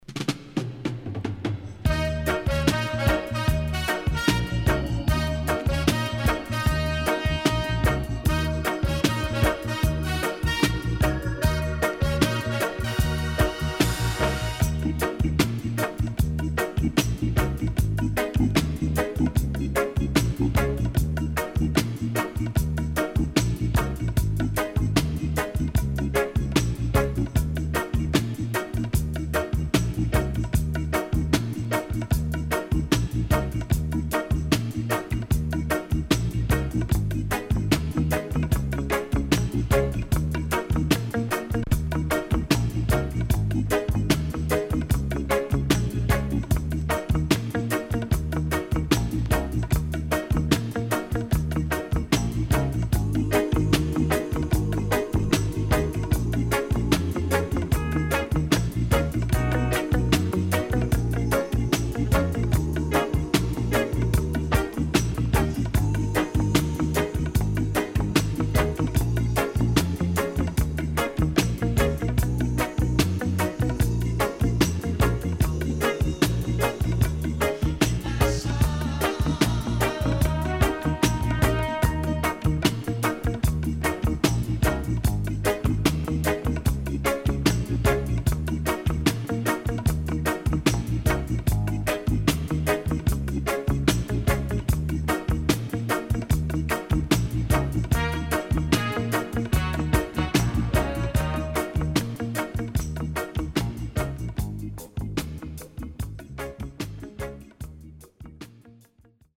HOME > Back Order [DANCEHALL DISCO45]